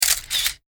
Photo_shutter.mp3